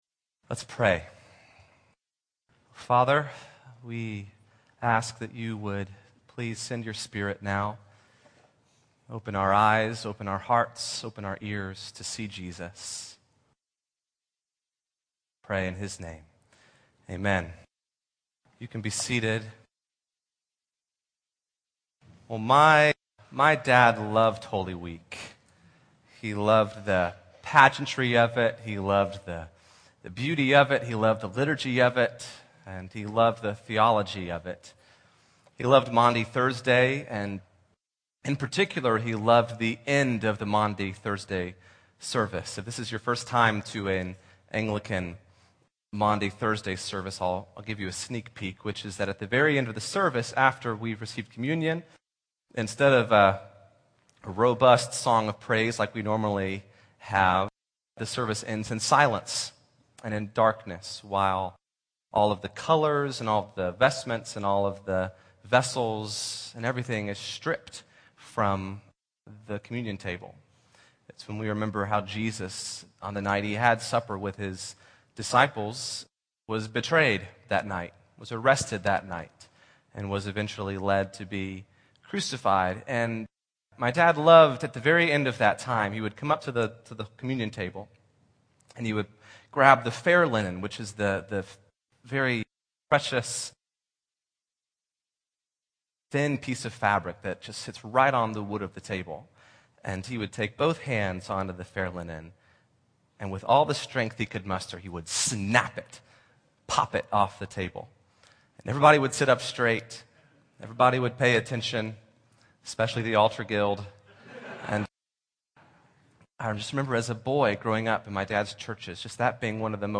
I had the privilege of preaching that night, and I wanted to share my message below. My goal was to faithfully point people to Jesus, and honor the work of Jesus in the life and through the death of my dad, especially over the painful months since his passing.